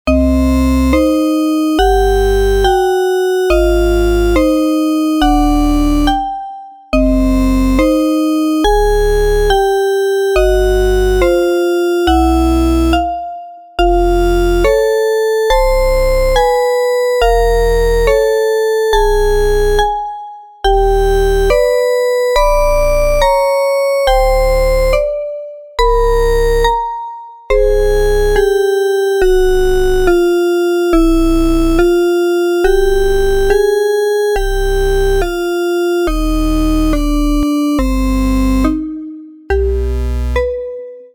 ホラー。怪談。ループ対応。